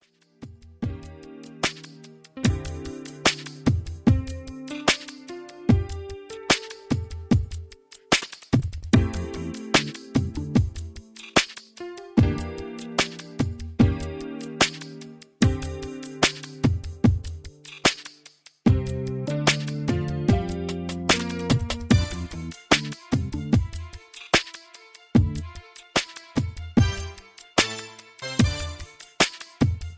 Neao Soul R&B